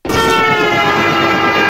train-sfx.ogg